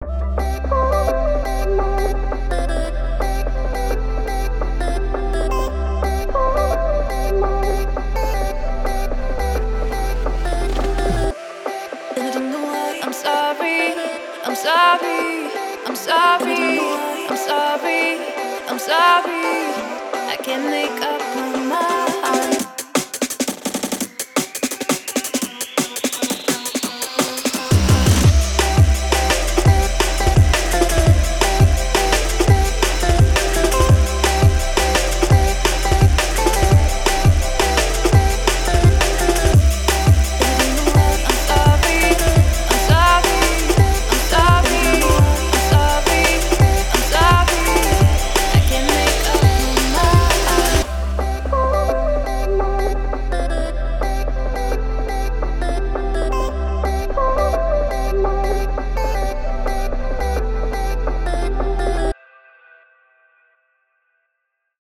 filled with explosive beats and frenzied samples.
• Explosive breakbeat loops
• Fiery samples
• Trippy one shots